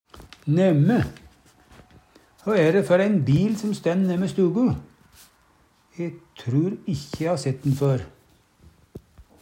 nemme - Numedalsmål (en-US)